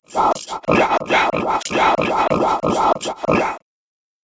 It gets me much closer to what I’m going for, but there’s still some wonkiness that I don’t know how to fix.
Using that program’s default settings, and dropping the full unedited sample in as a carrier (it automatically loops short files), this is the outcome:
I suspect those weird low-pitched sounds are where the speaker’s inflections (?) aren’t matching up with loops of the audio.